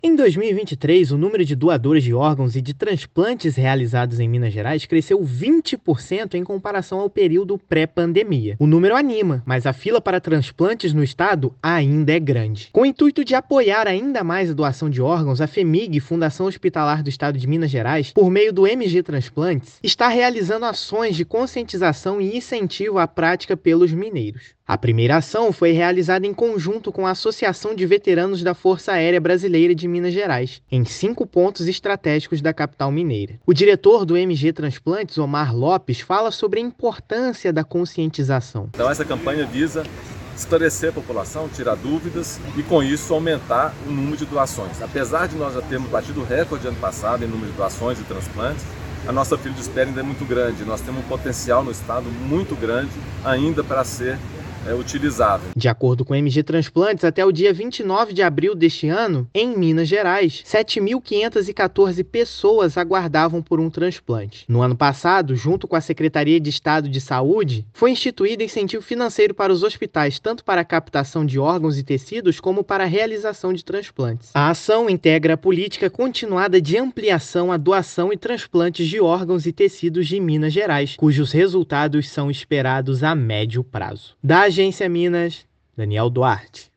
[RÁDIO] MG Transplantes e veteranos da Força Aérea promovem ação conjunta pela doação de órgãos
Estado teve alta no número de transplante e quer diminuir ainda mais lista de espera. Ouça matéria de rádio.